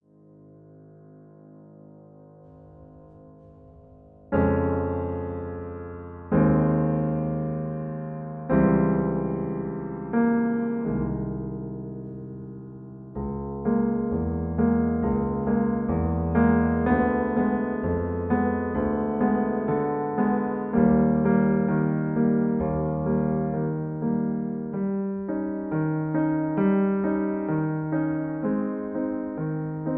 MP3 piano accompaniment
in A flat Major